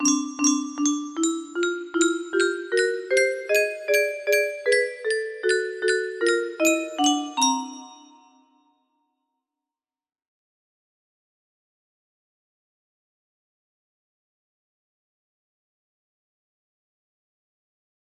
UK 3.1 music box melody